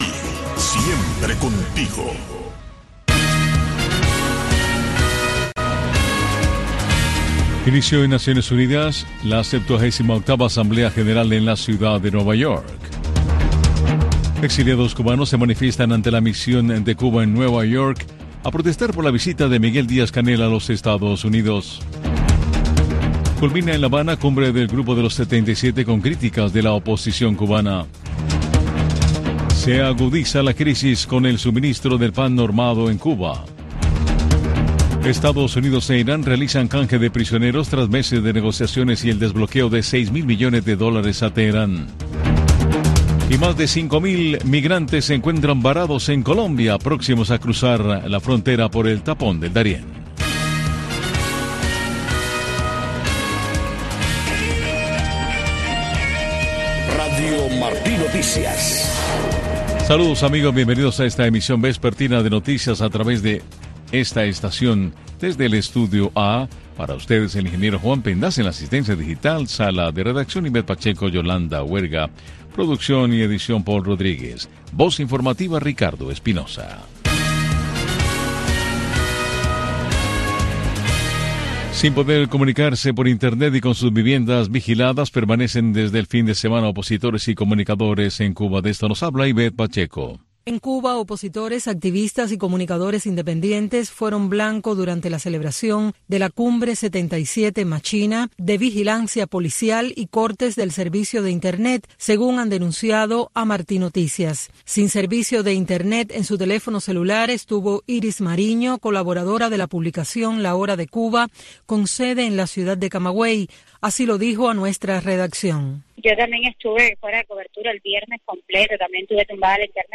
Un resumen de las noticias más importantes de Cuba y el mundo.